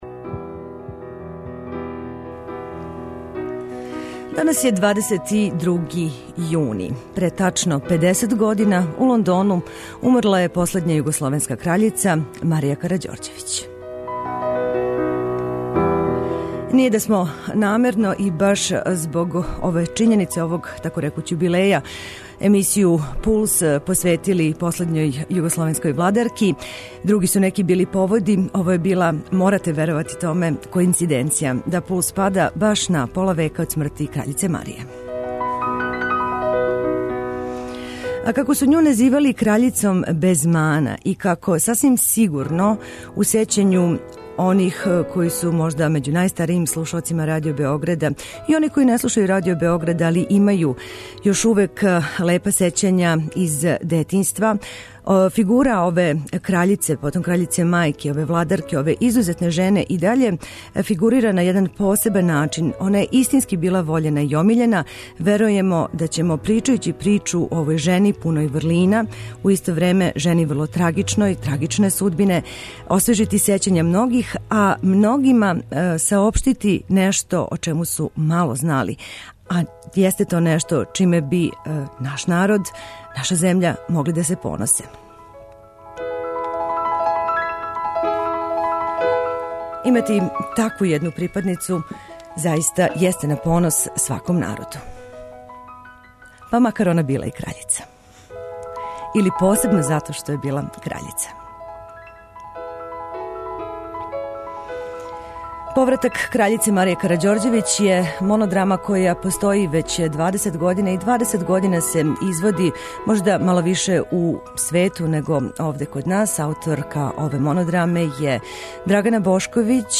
а у емисији ћете чути и одломке из монодраме коју са великим успехом изводи драмска првакиња Љиљана Стјепановић.